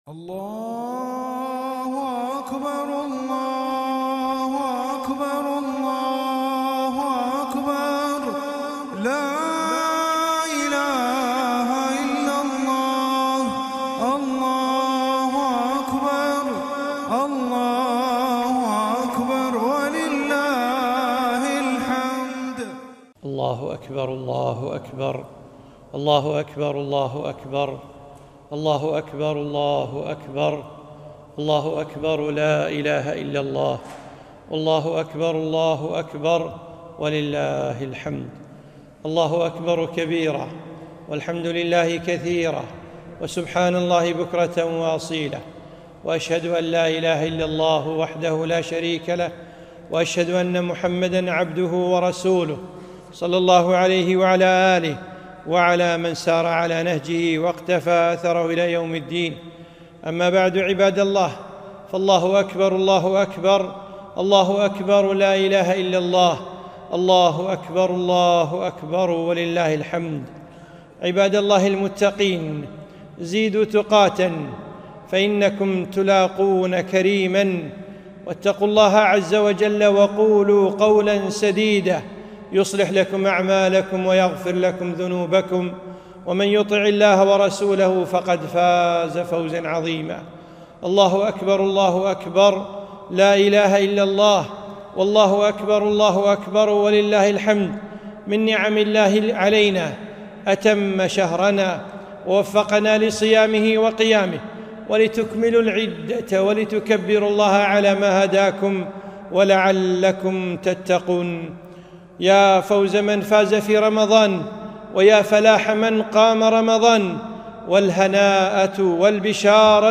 خطبة عيد الفطر المبارك - ١ شوال ١٤٤٣ هـ ٢ - ٥ - ٢٠٢٢م